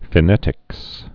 (fĭ-nĕtĭks)